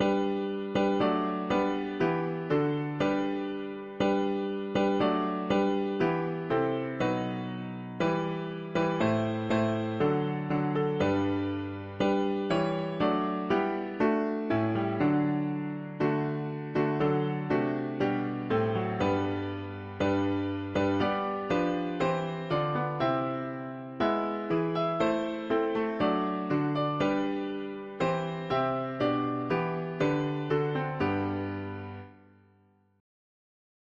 Key: G major
Tags english theist 4part autumn